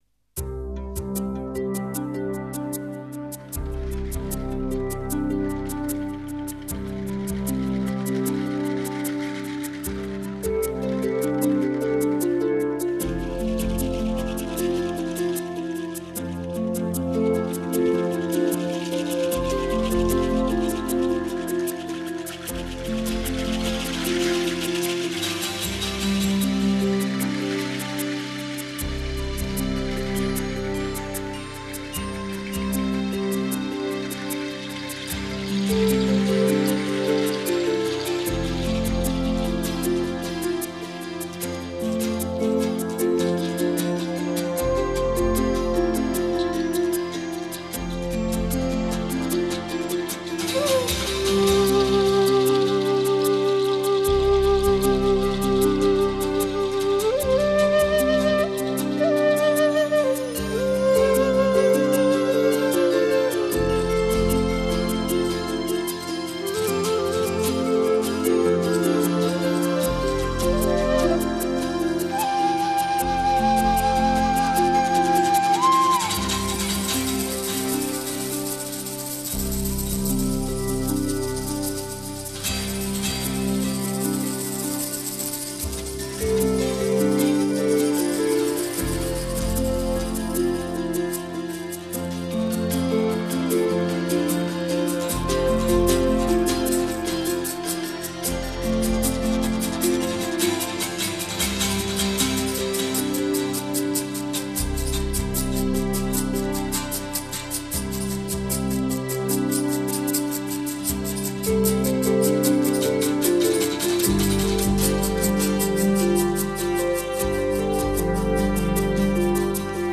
live in Carpi
tastiere, samples, chitarra, tin whistle
voce recitante
flauti e sax soprano
percussioni
Performance polimediale